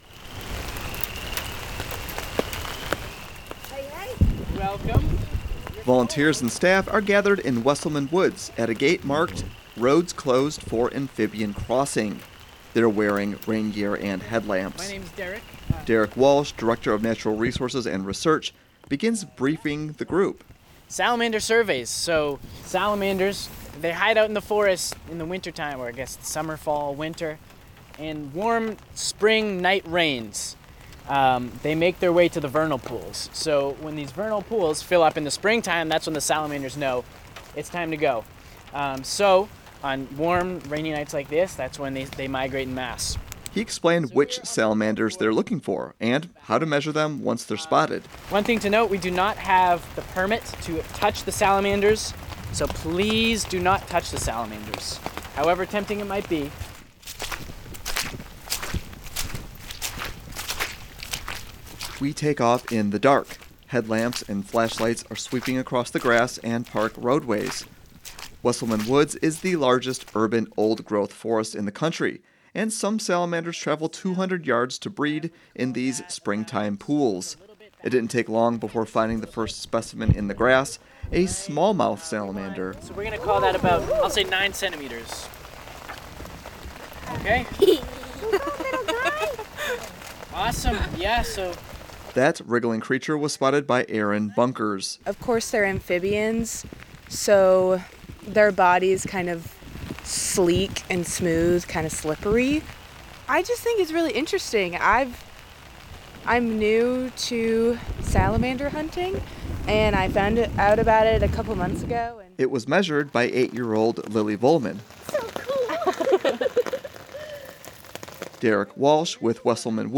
Staff with Evansville’s Wesselman Woods counts amphibians every March on warm rainy nights with citizen scientists.
Rain is pattering off hoods and umbrellas as they don headlamps and check flashlights.